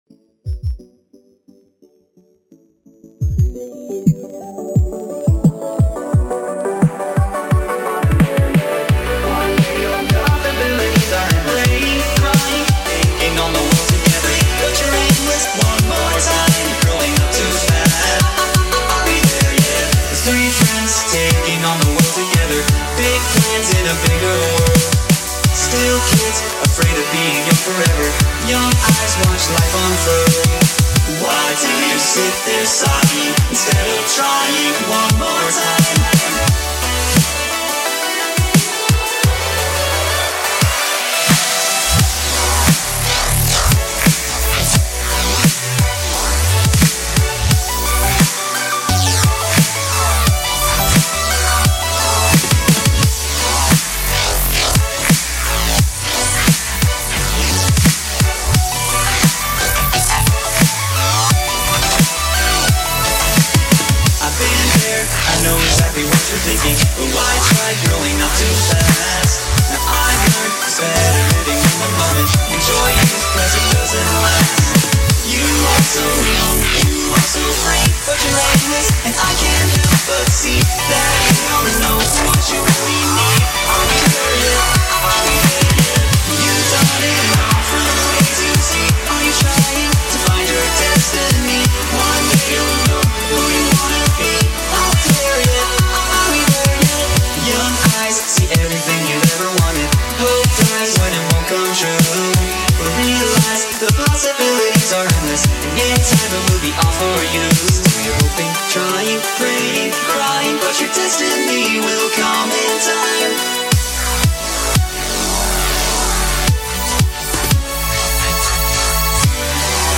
BPM - 174
Genre - Drumstep